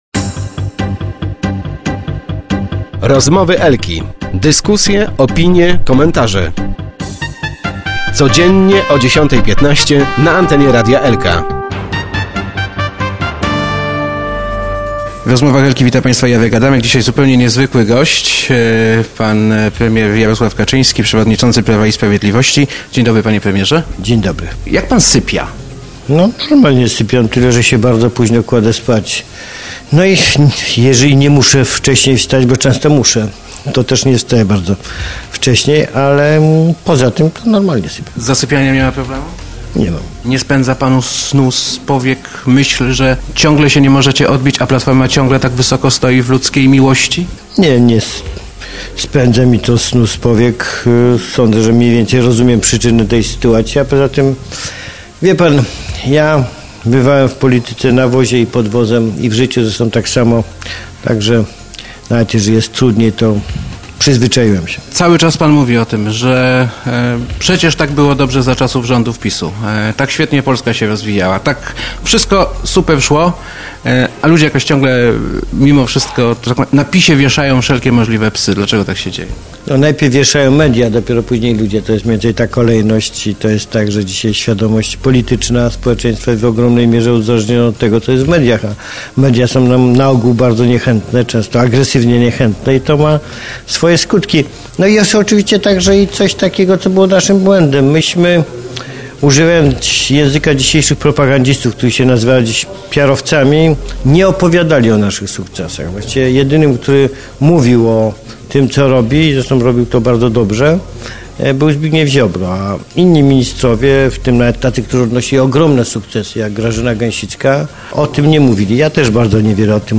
Prawdziwa równość to równość portfeli – mówił w Rozmowach Elki prezes PiS Jarosław Kaczyński. Były premier zapewnia, że gdyby do kryzysu doszło za rządów jego partii, walka z nim byłaby dużo skuteczniejsza.